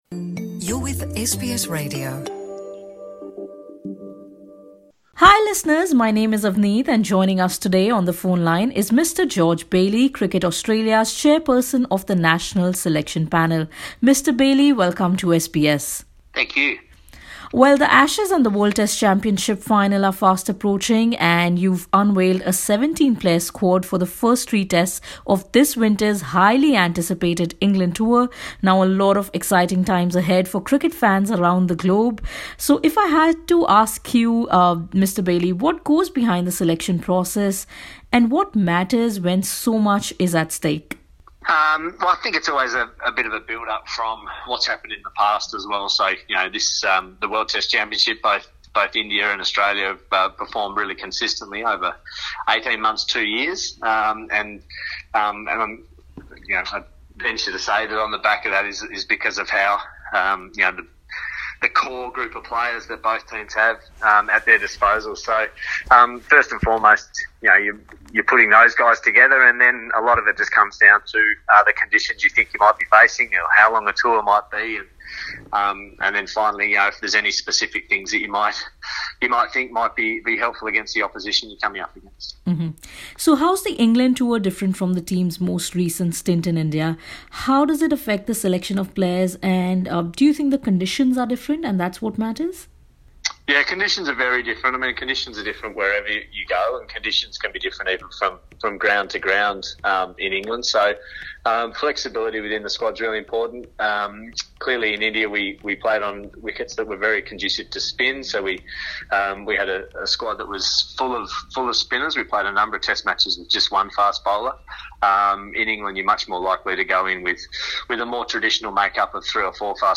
ਆਸਟ੍ਰੇਲੀਆ ਦੀ ਮਰਦਾਂ ਦੀ ਕ੍ਰਿਕਟ ਟੀਮ ਦੇ ਰਾਸ਼ਟਰੀ ਚੋਣ ਪੈਨਲ ਦੇ ਚੇਅਰਪਰਸਨ, ਜੋਰਜ ਬੇਲੀ ਨੇ ਜੂਨ ਵਿੱਚ ਭਾਰਤ ਦੇ ਖਿਲਾਫ ਹੋਣ ਵਾਲੇ ਆਈ ਸੀ ਸੀ ਵਿਸ਼ਵ ਟੈਸਟ ਚੈਂਪੀਅਨਸ਼ਿਪ ਦੇ ਫਾਈਨਲ ਤੋਂ ਪਹਿਲਾਂ ਆਸਟ੍ਰੇਲੀਅਨ ਟੀਮ ਦੀ ਕਾਰਗੁਜ਼ਾਰੀ ਤੇ ਇਹ ਮੈਚ ਜਿੱਤਣ ਦੀਆਂ ਸੰਭਾਵਨਾਵਾਂ ਬਾਰੇ ਐਸ ਬੀ ਐਸ ਪੰਜਾਬੀ ਨਾਲ਼ ਵਿਸ਼ੇਸ਼ ਗੱਲਬਾਤ ਕੀਤੀ ਹੈ।